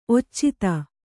♪ occita